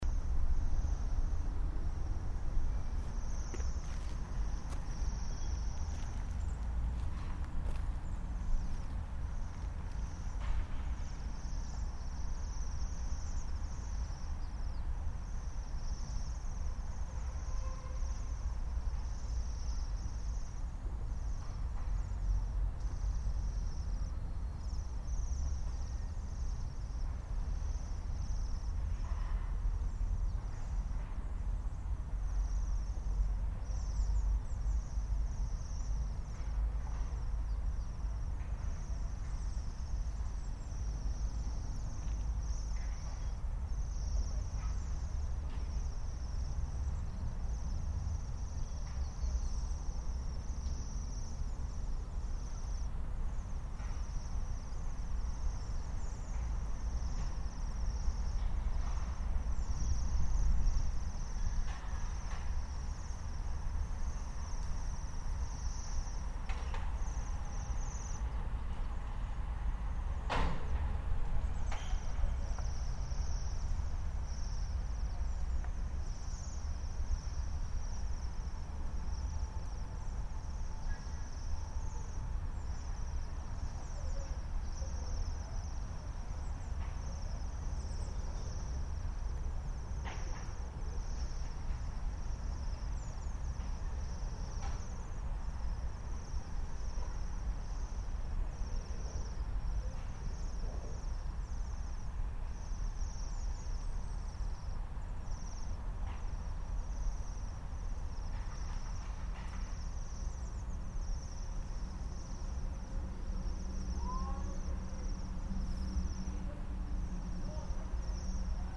Silkehale
flok set & hørt en grå vinterdag i Botanisk Have (Øster Farimagsgade, K). Sangen bliver ofte sammenlignet med lyden af en sølvfløjte, og noget er der om snakken:
Dette klip er min egen mp3-optagelse fra haven. Der er vel ca. hundrede fugle, som antagelig er vintergæster fra det høje nord. Afstanden til fuglene, som sidder i en trætop, er ca. ti meter.